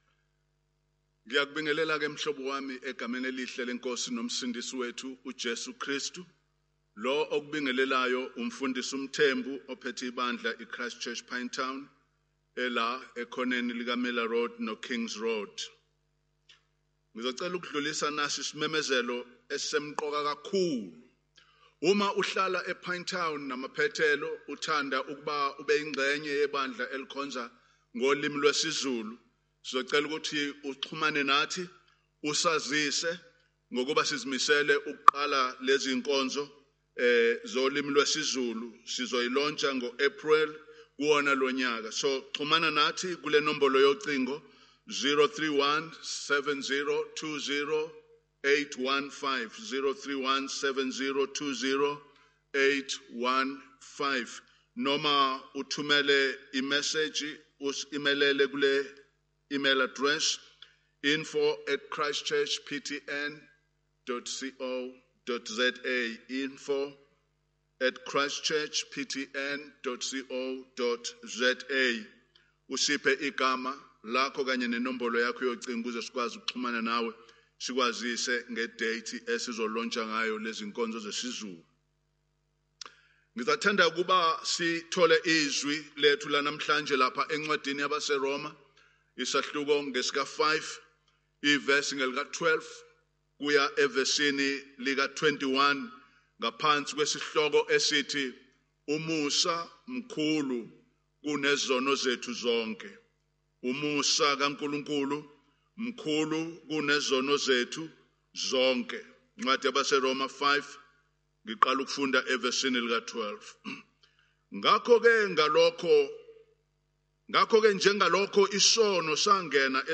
Passage: Romans 5:12-21 Event: Zulu Sermon « God’s Work and Ours